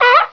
seal.wav